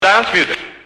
Scratch voice soundbank 2
Free MP3 scratch Dj's voices sound effects 2